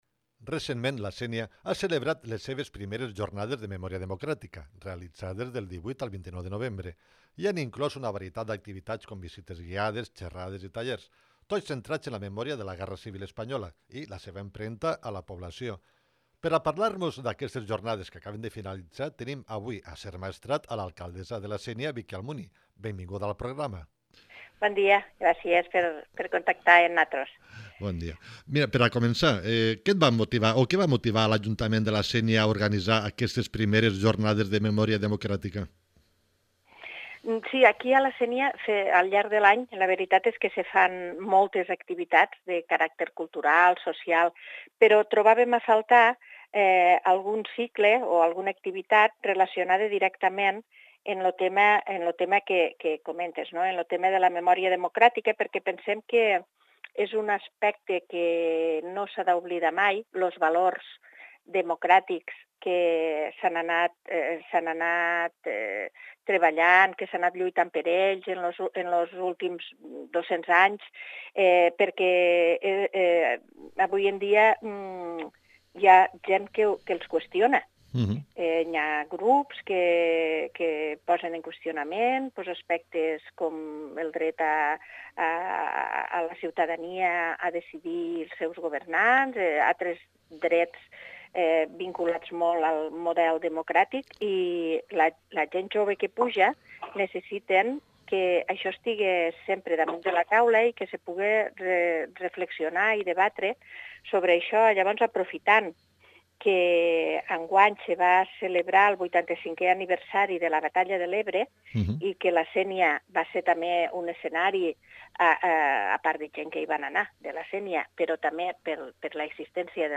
Entrevista a Victòria Almuni, alcaldessa de la Sénia en motiu de les jornades de memòria democràtica